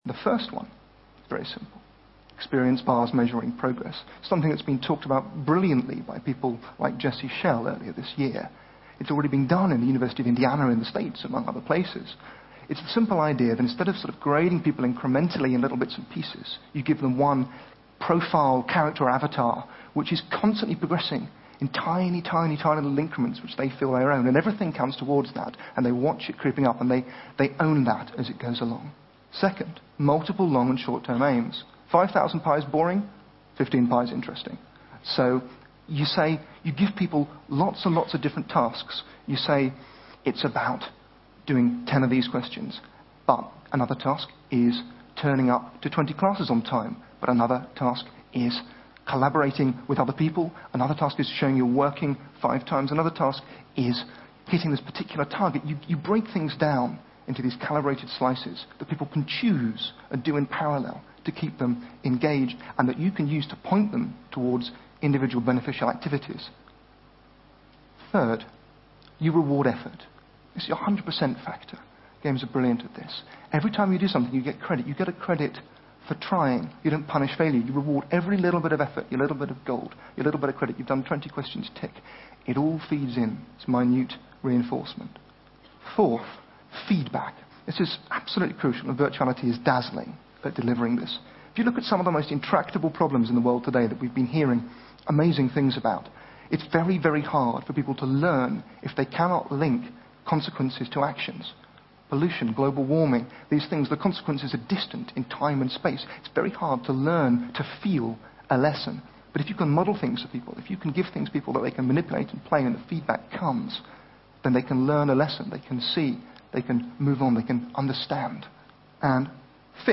TED演讲:游戏奖励大脑的7种方式(4) 听力文件下载—在线英语听力室